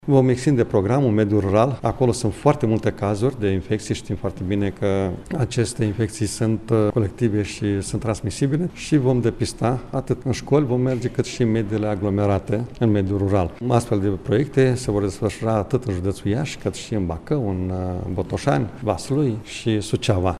La rândul său, preşedintele Consiliului Judeţean Iaşi, Maricel Popa, a declarat că se vor achiziționa truse medicale ce vor fi folosite începând cu luna septembrie în campania derulată în mediul rural pentru depistarea hepatitelor: